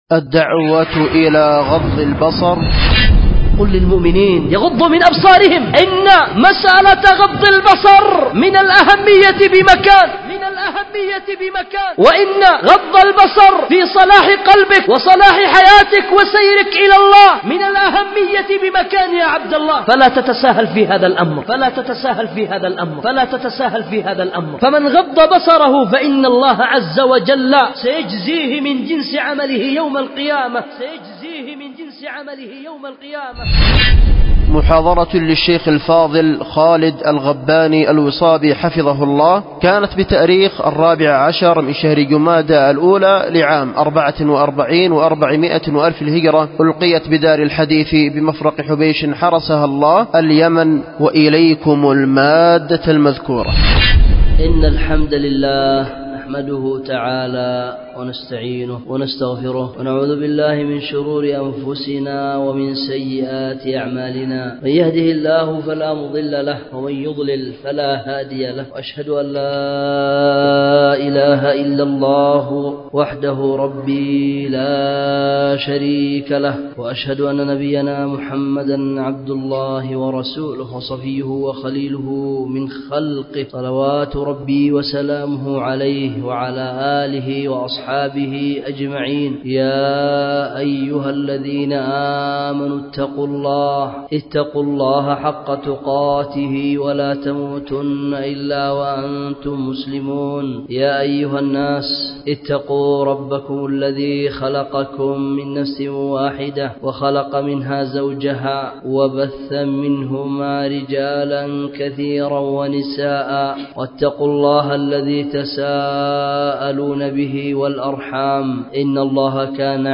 ألقيت بدار الحديث بمفرق حبيش